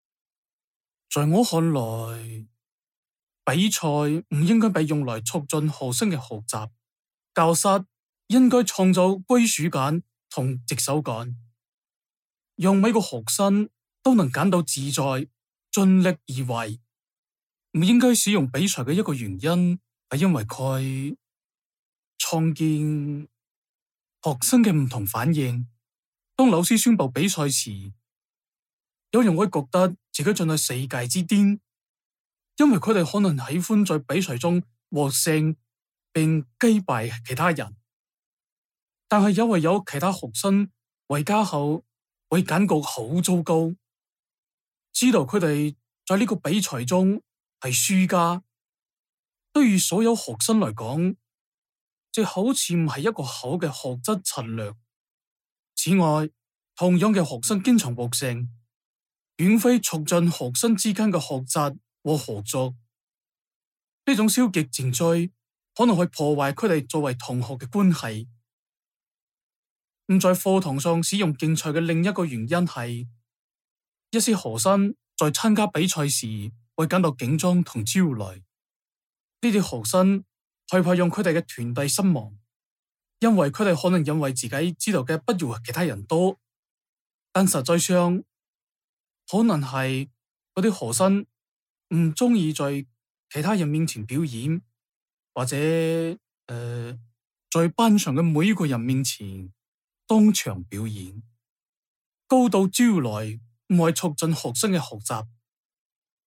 The response effectively communicates clear and logically sequenced ideas delivered with a consistent flow of speech, few pauses, intelligible pronunciation and appropriate intonation.
Examples of such errors include some inconsistency in the flow of speech and a few pauses.